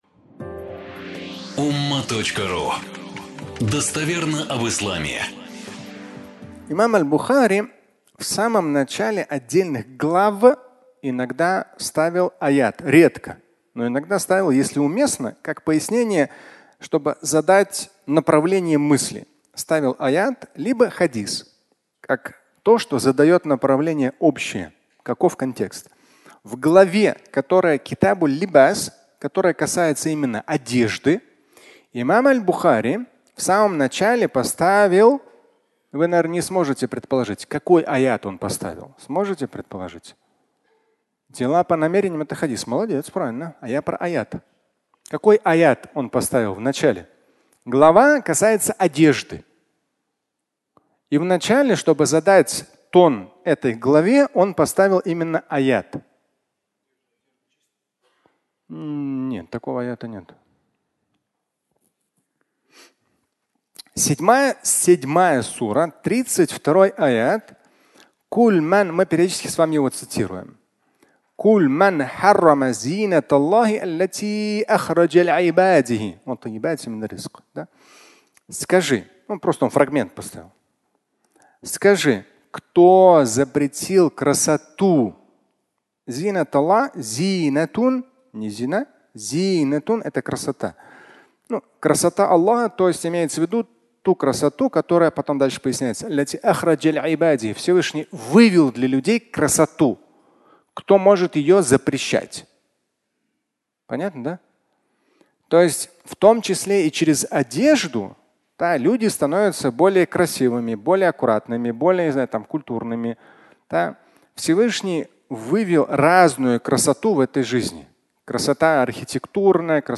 Расточительство и высокомерие (аудиолекция)